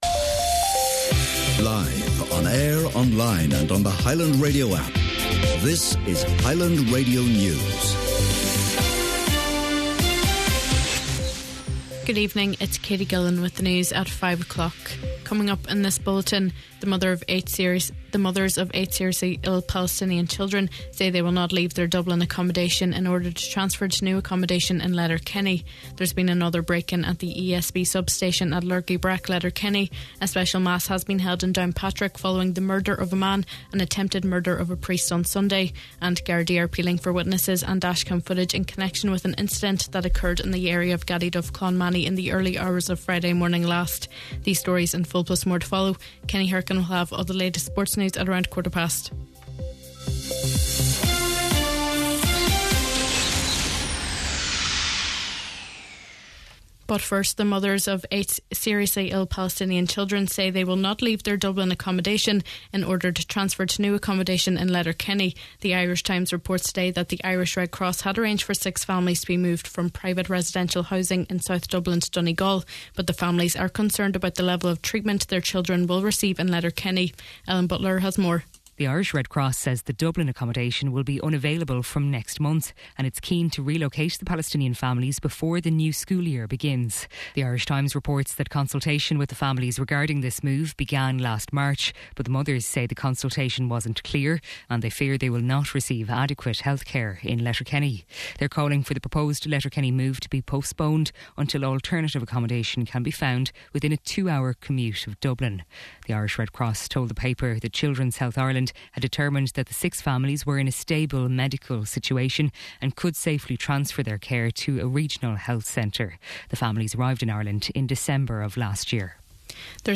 Main Evening News, Sport and Obituary Notices – Tuesday August 12th